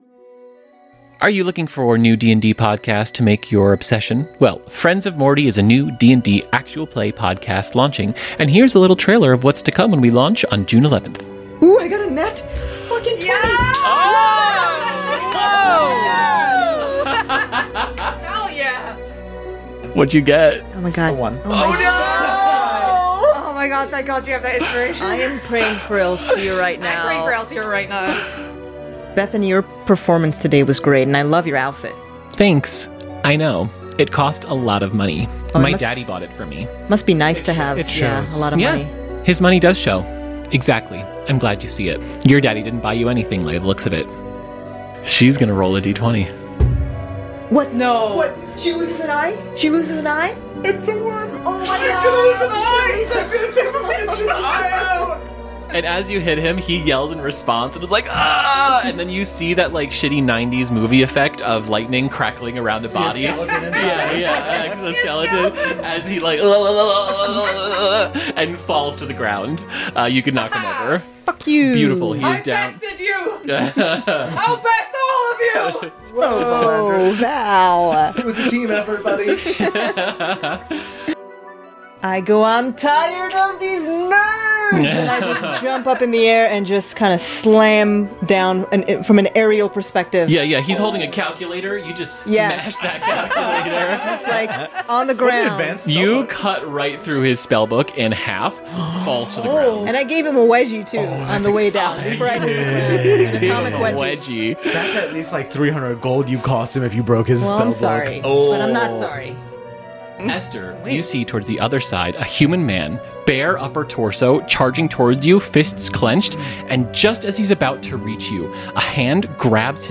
Format: Audio RPG
Writing: Improvised
Voices: Full cast
Soundscape: Music